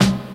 Royality free snare drum sample tuned to the G note. Loudest frequency: 1096Hz